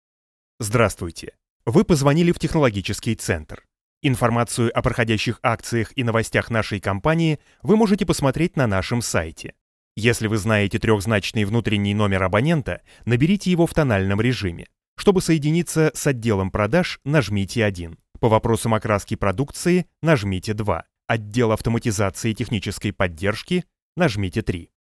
My voice is a clean, accent-free native Russian, adaptable across a wide tonal range – from formal and authoritative to engaging and friendly.
My setup includes an Oktava MKL-5000 tube microphone which gives a rich, warm sound to my voice, ideal for narration, commercials, and corporate reads.
IVR Phone System Demo